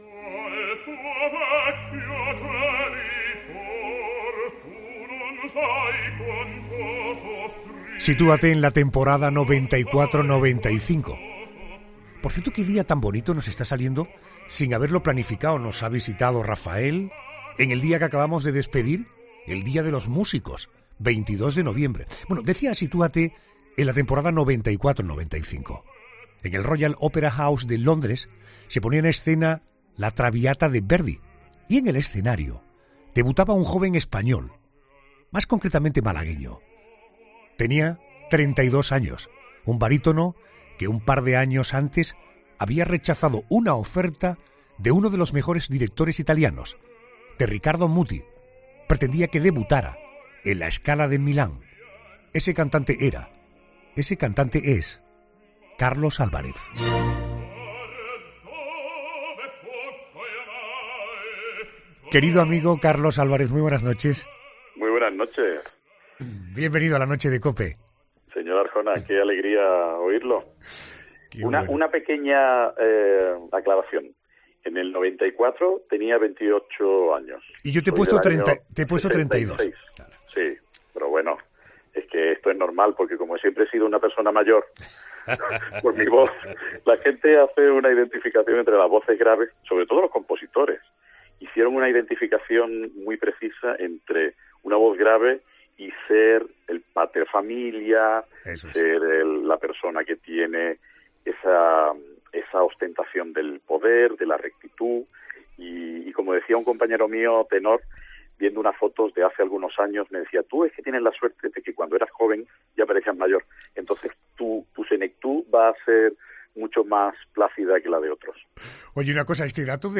El barítono malagueño ha pasado por los micrófonos de 'La Noche de COPE' para hablarnos de su pasión: la ópera